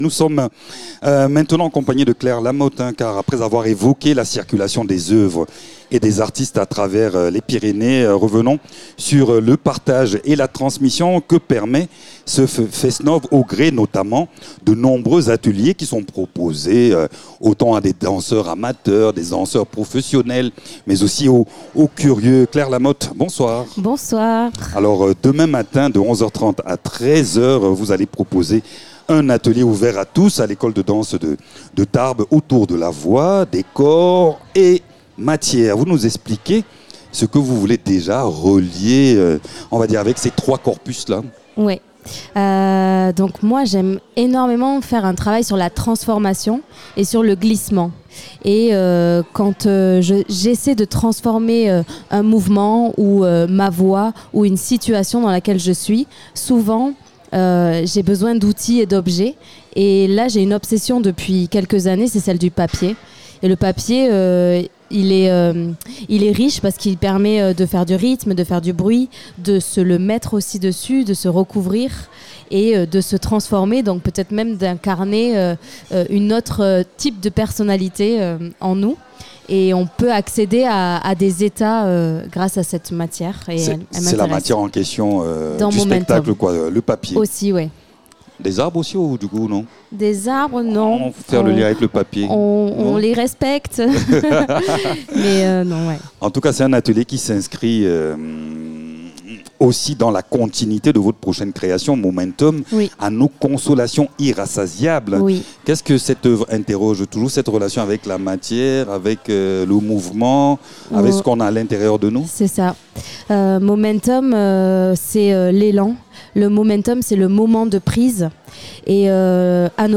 Cette interview offre un éclairage sensible sur son approche, à la croisée du geste, du souffle et de la matière.